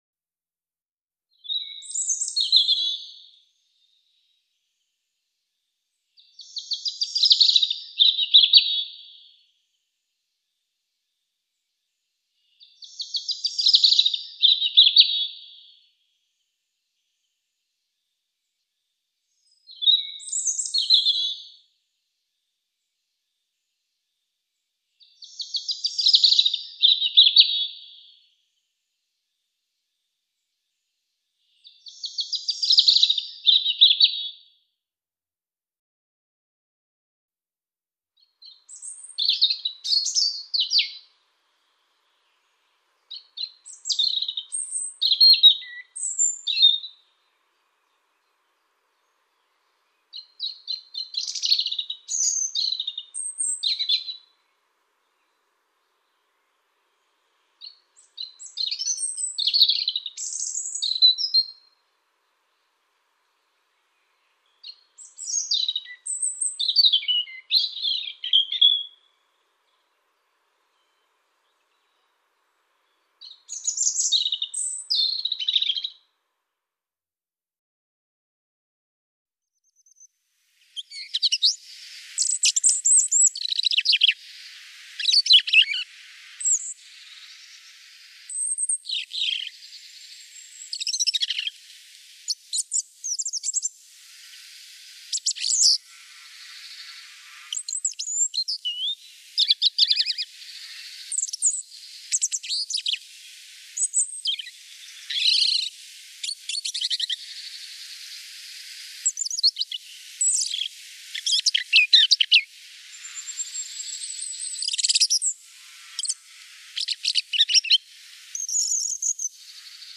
Rouge-gorge familier
Son chant
Oiseau très matinal, il chante dès l’aube et est souvent le dernier visible au crépuscule.
rougegorge_familier_1.mp3